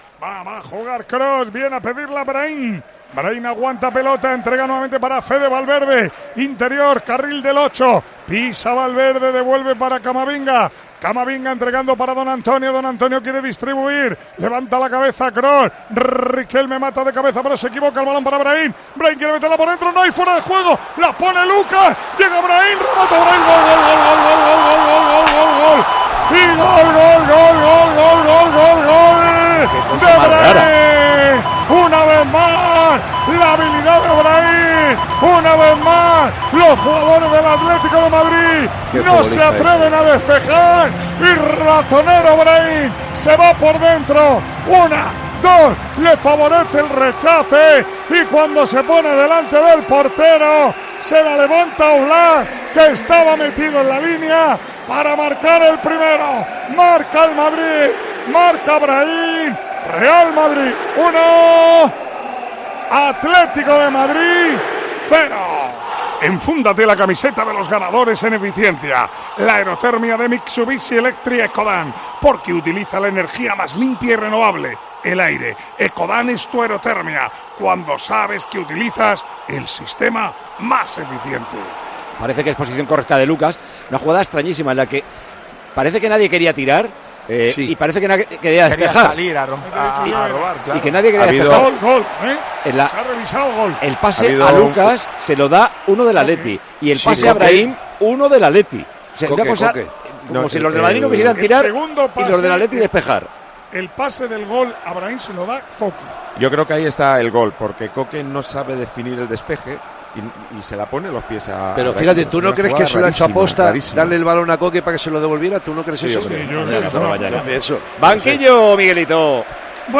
Micrófono de COPE en el Santiago Bernabéu.